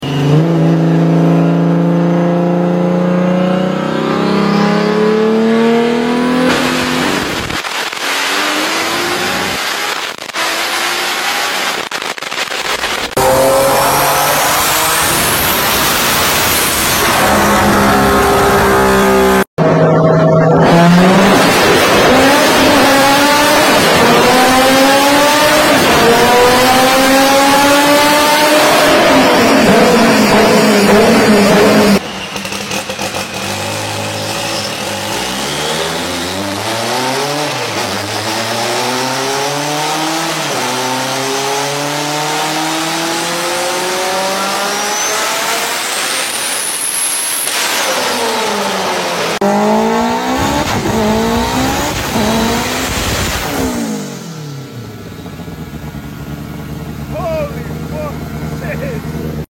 Top 5 Craziest JDM Dyno Sound Effects Free Download